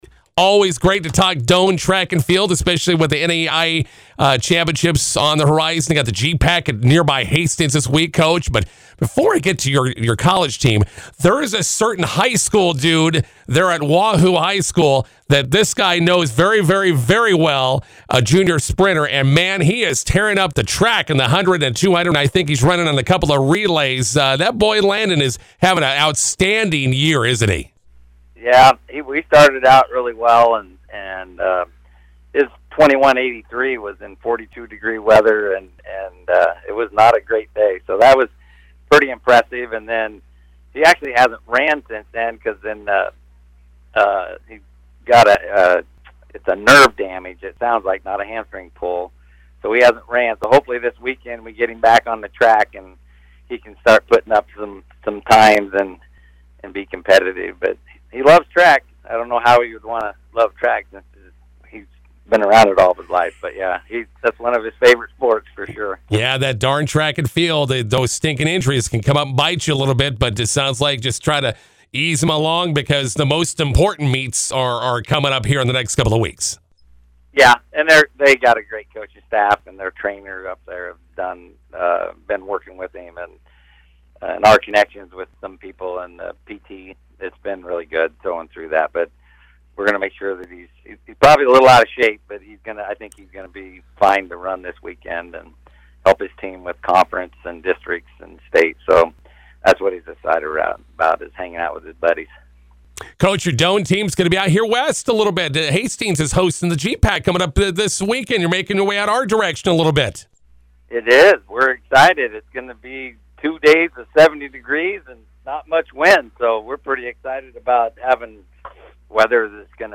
INTERVIEW: Doane Track and Field shooting for season sweep of conference team titles.